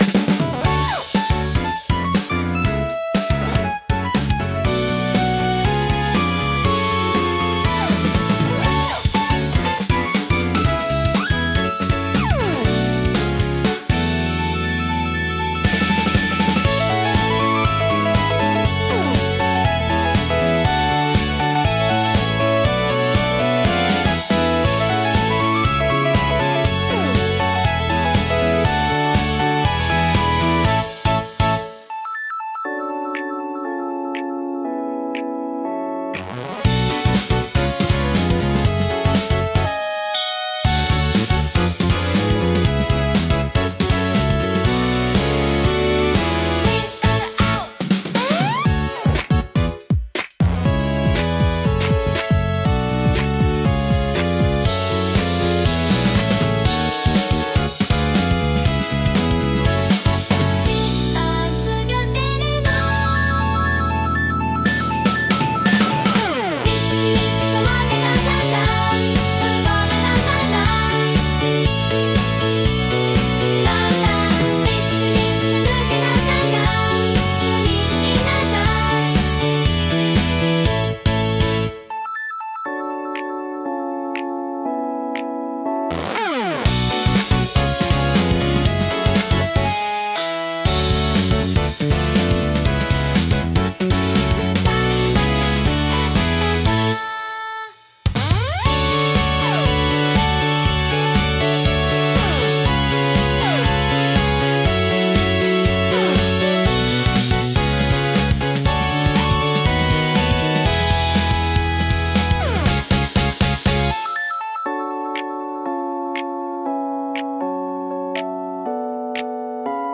親しみやすく軽快な空気感を演出したい場面で活躍します。